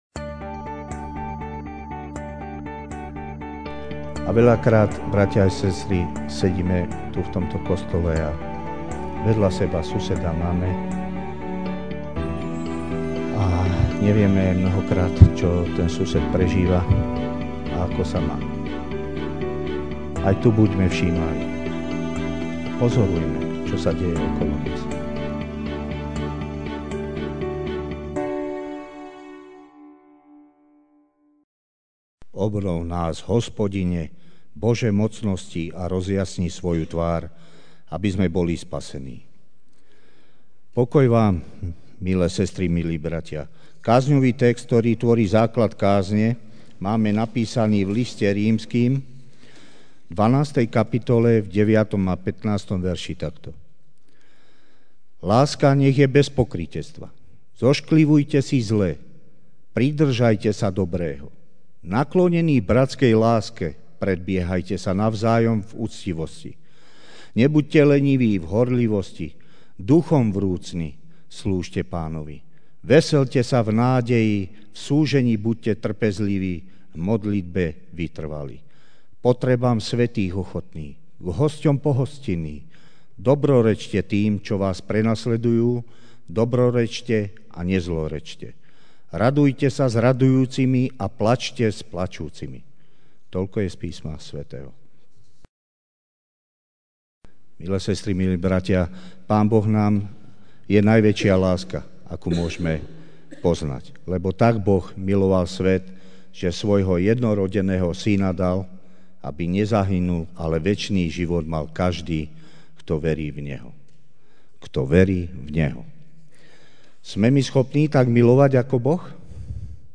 Večerná kázeň: Milovať blížneho (Rím. 12, 9-15) Láska nech je bez pokrytectva!